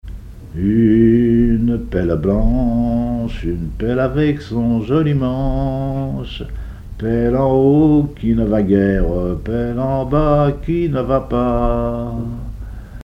Haleurs ou dameurs travaillant ensemble rythmiquement
circonstance : maritimes
Pièce musicale inédite